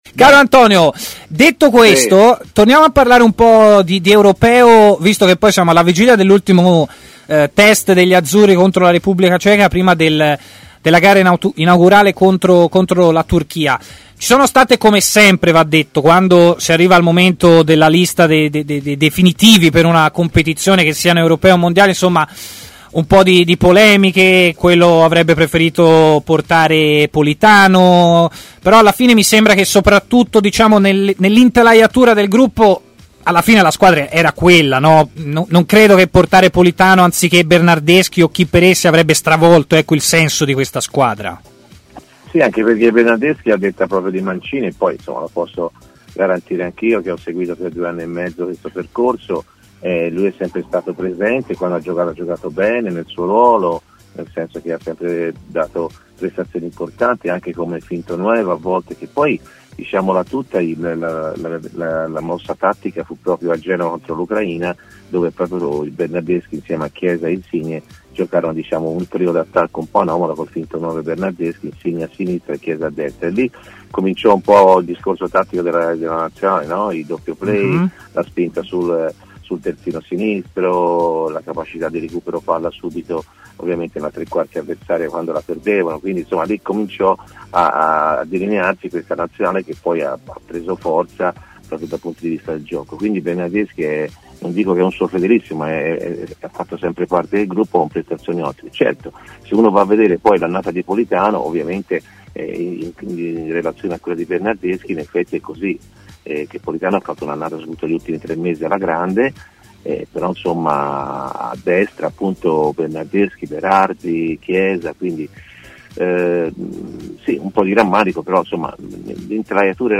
L'ex centrocampista Antonio Di Gennaro, oggi commentatore tv e opinionista TMW Radio, ha parlato in diretta iniziando dalle convocazioni di Mancini per l'Europeo